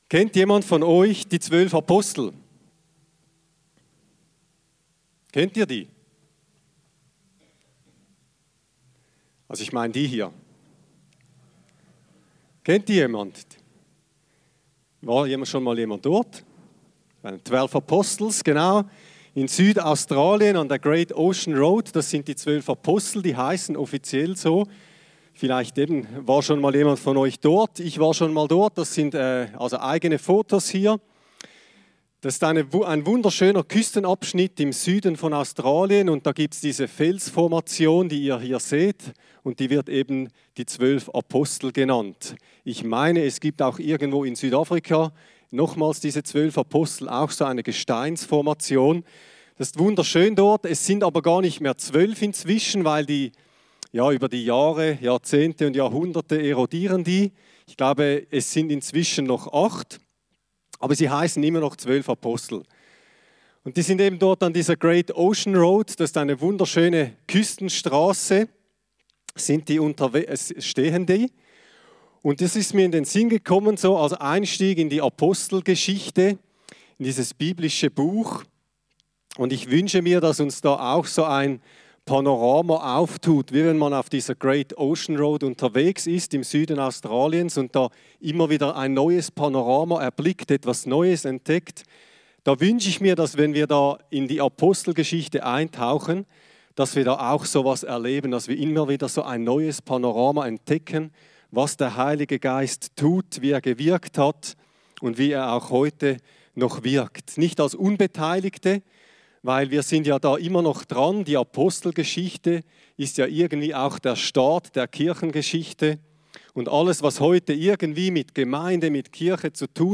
Predigt-6.4.25_.mp3